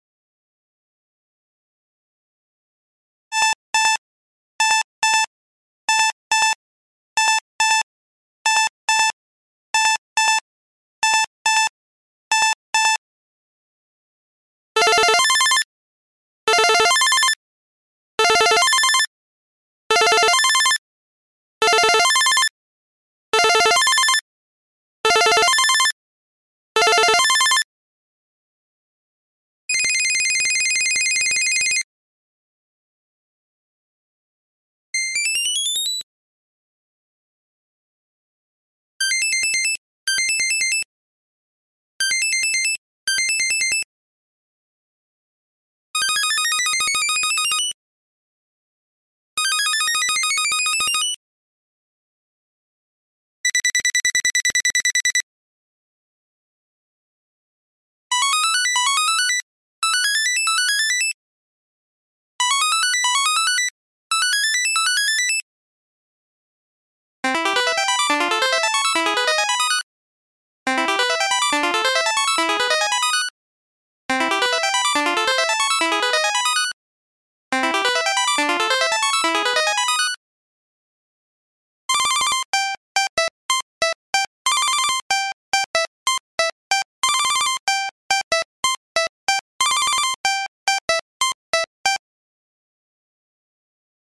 [Samsung 三星][Galaxy SmartTag]Samsung Galaxy SmartTag sound effects free download